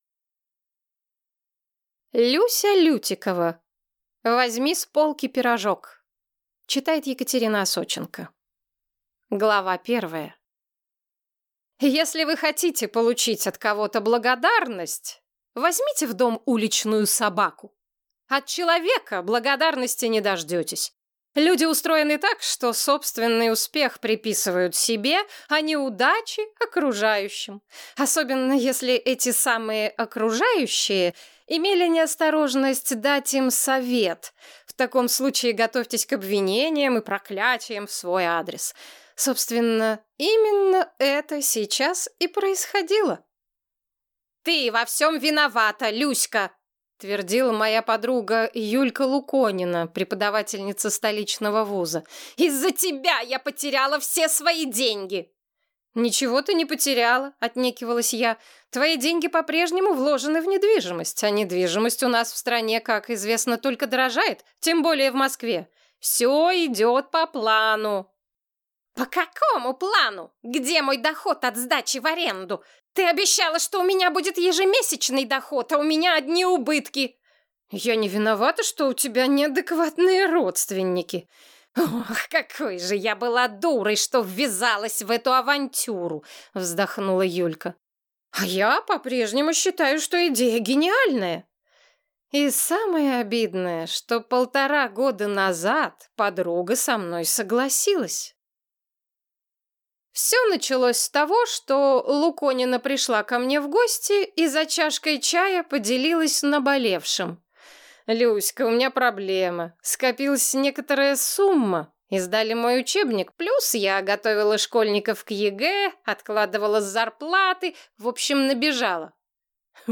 Аудиокнига Возьми с полки пирожок | Библиотека аудиокниг
Прослушать и бесплатно скачать фрагмент аудиокниги